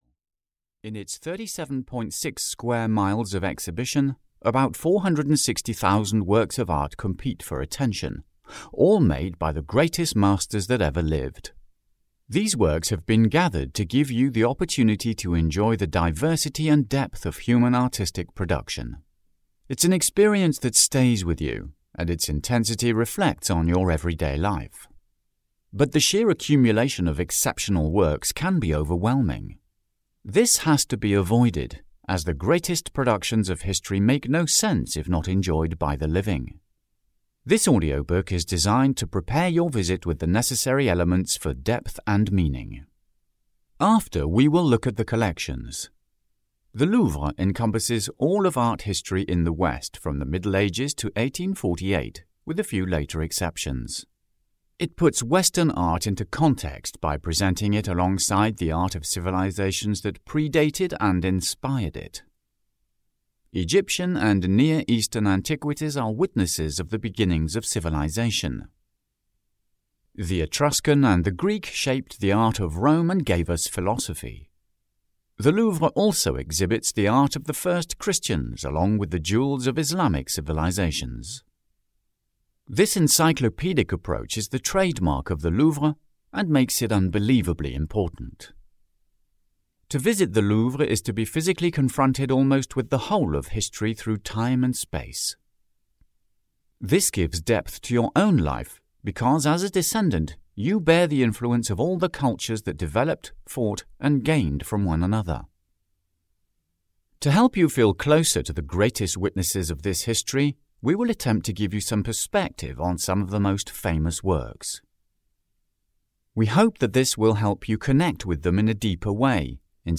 The Secret Story of the Musee du Louvre (EN) audiokniha
Ukázka z knihy